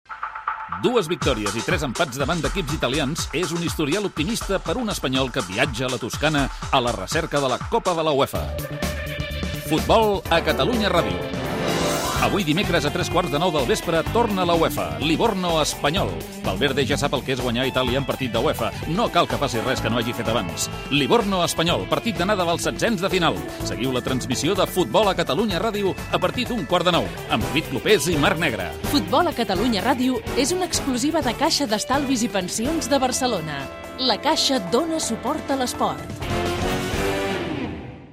Promoció del partit de futbol masculí Livorno - R.C.D.Espanyol, dels setzens de final de l'Europa League